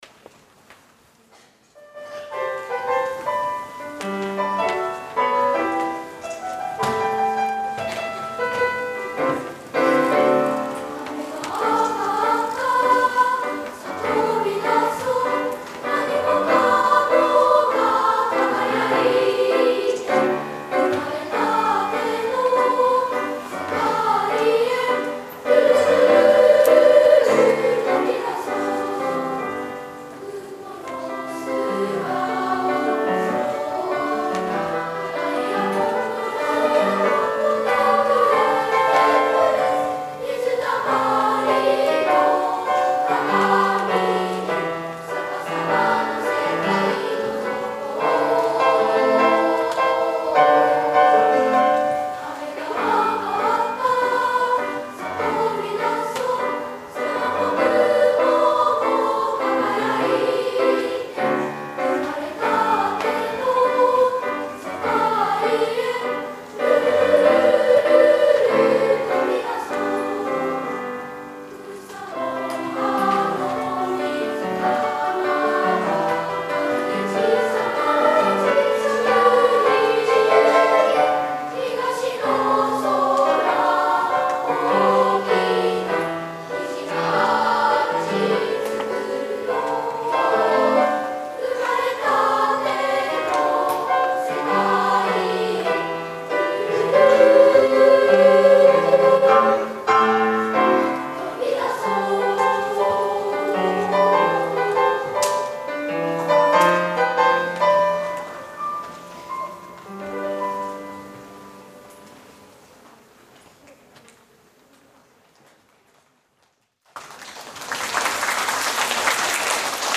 11月20・21日に『2012年度　湘南学園小学校音楽会』が小学校ホールにて開催されました。
※合唱の音声を聴くことができるようにしました。